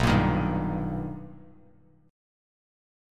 B+M7 chord